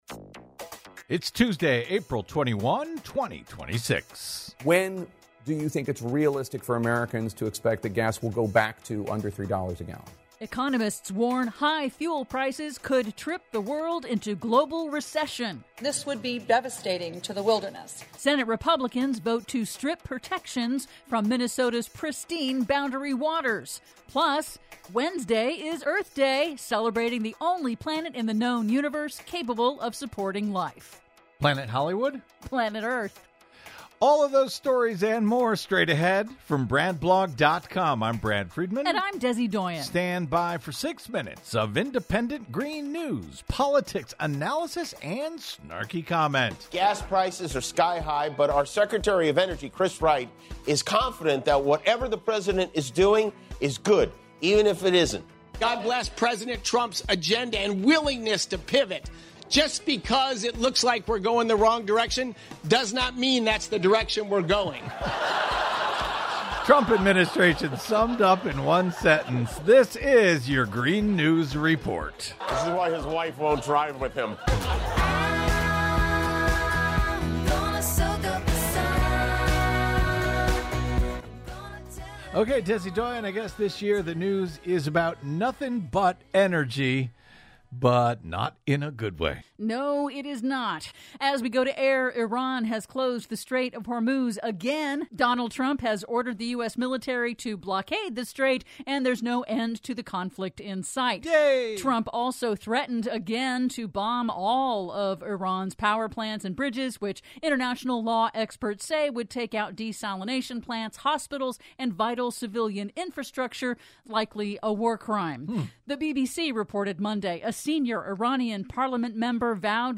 IN TODAY'S RADIO REPORT: Economists warn high fuel prices could trip the world into global recession; Senate Republicans vote to strip protections from Minnesota's pristine Boundary Waters Canoe Area; 16 years after catastrophic BP Oil Spill, Trump administration approves BP's new ultra deepwater drilling project; PLUS: Earth Day 2026 celebrates the only planet in the known universe capable of supporting life...